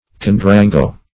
Search Result for " condurango" : The Collaborative International Dictionary of English v.0.48: Condurango \Con`du*ran"go\, n. (Med.)